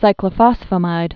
(sīklə-fŏsfə-mīd)